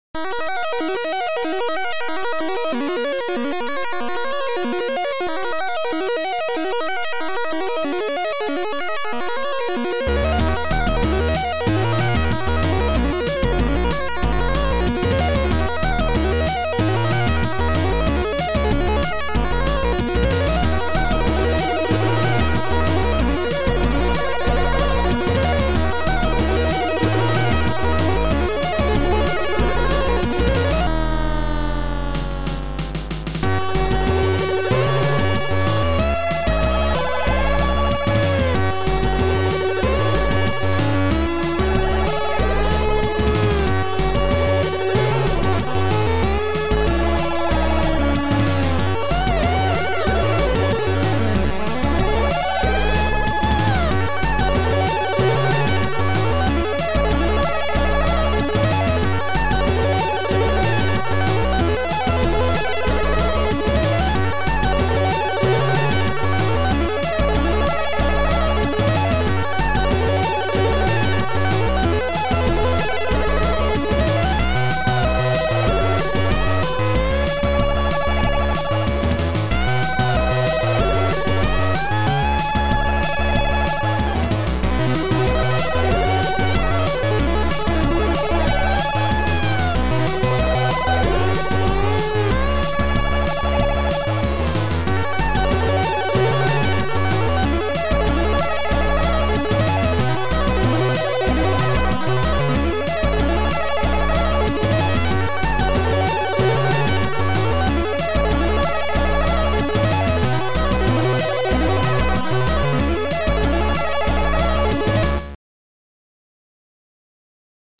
Sound Format: Noisetracker/Protracker
Sound Style: Sorrow / Mellow Chip / Synth Chip